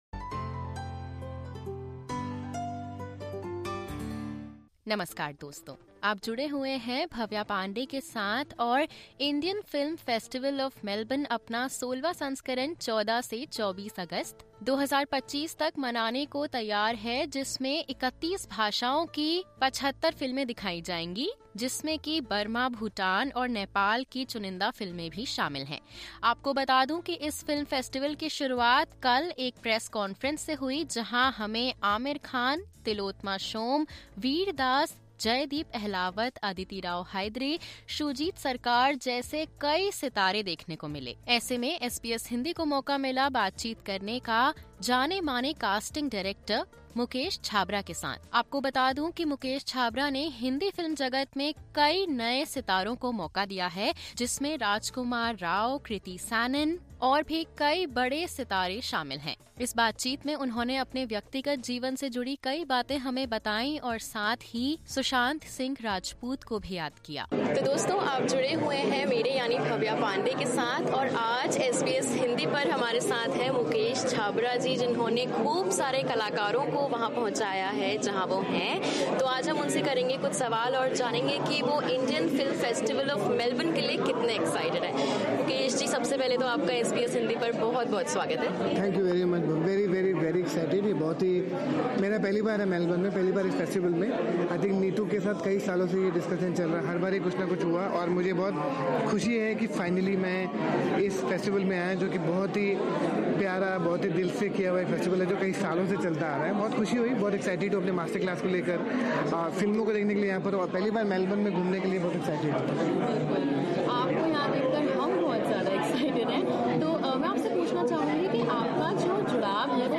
With the 16th edition of the Indian Film Festival of Melbourne (IFFM) underway, SBS Hindi caught up with Indian casting director Mukesh Chhabra, who spoke about his career choices, passion for theatre, the mental health challenges faced by newcomers and the art of discovering and launching fresh talent. He also revealed the name of the Bollywood star he considers his all-time favourite.